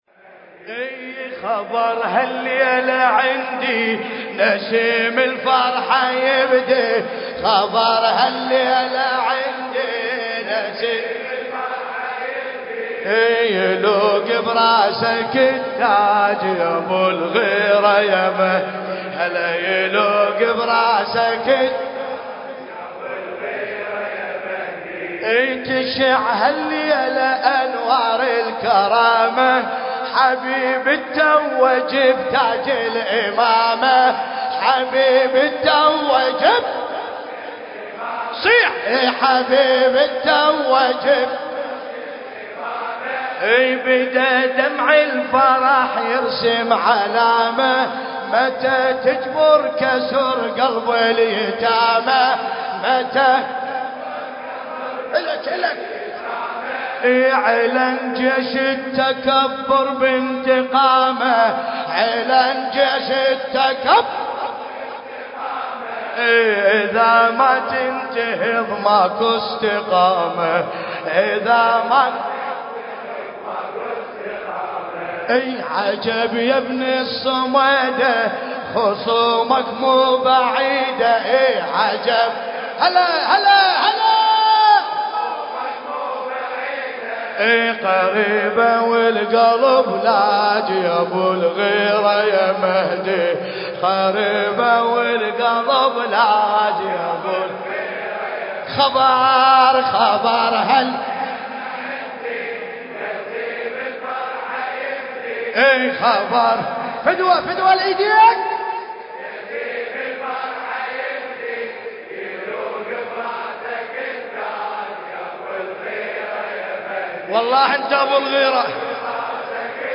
المكان: حسينية داوود العاشور/ البصرة